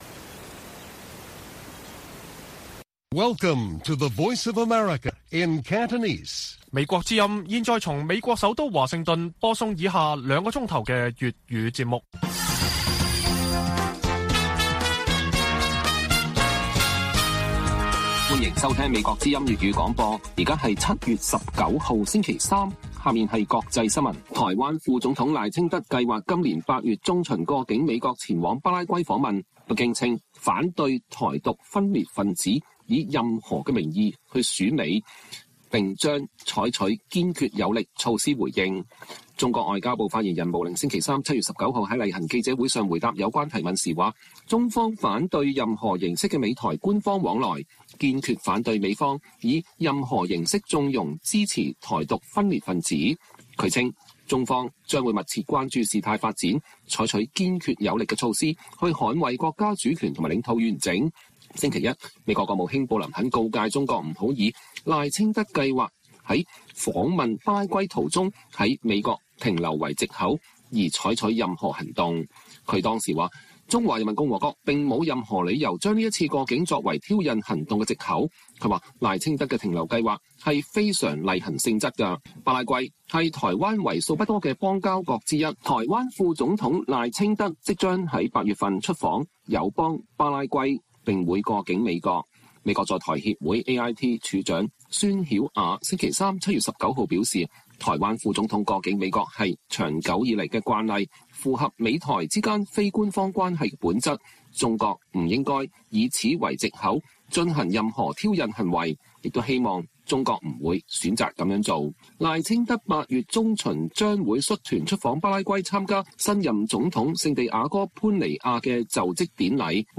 粵語新聞 晚上9-10點: 被香港通緝的抗爭者呼籲港人要維持抗爭信念